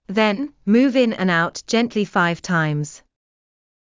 ｾﾞﾝ ﾑｰﾌﾞ ｲﾝ ｴﾝ ｱｳﾄ ｼﾞｪﾝﾄﾘｰ ﾌｧｲﾌﾞ ﾀｲﾑｽ